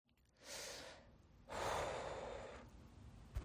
呼气/Breathing/Exhale
描述：呼气声音，可用于动画动漫等视频后期配音。
标签： 人生 呼吸 后期配音
声道立体声